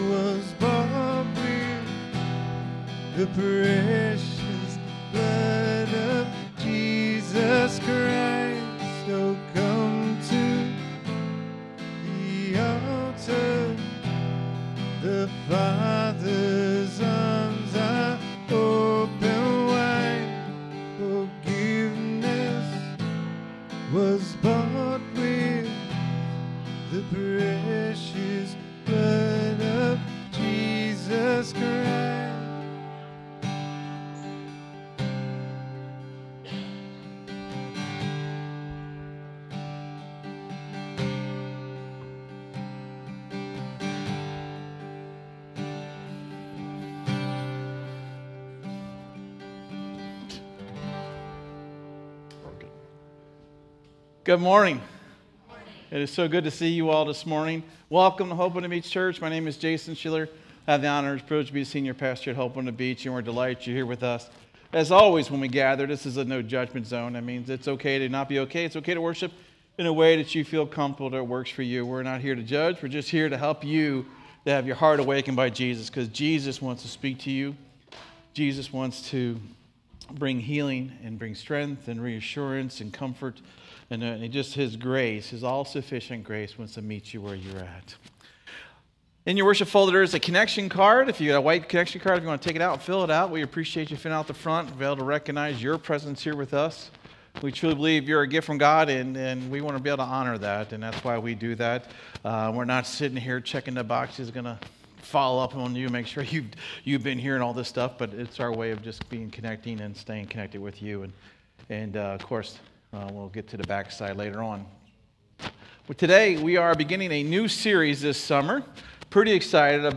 SERMON DESCRIPTION The ministry of John the Baptist marked the transition from corporate religious practices to individualpersonal faith.